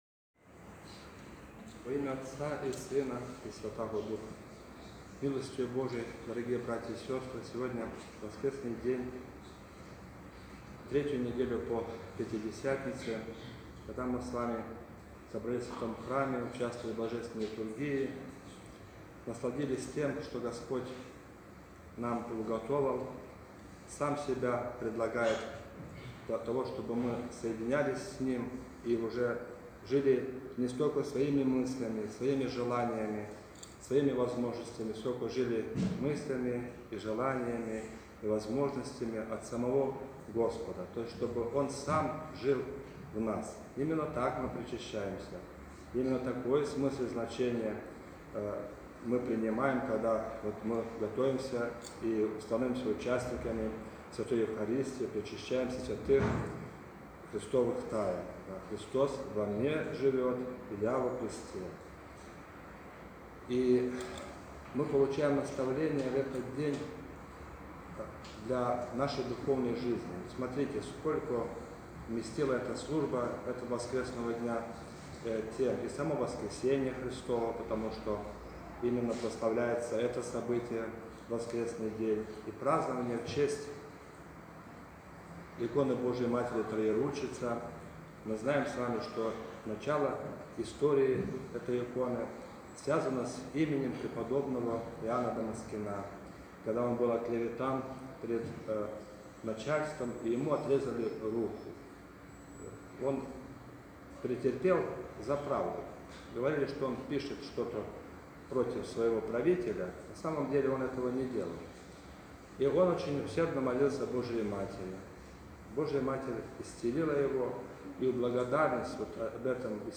Проповедь настоятеля храма в Неделю 3-ю по Пятидесятнице Вконтакте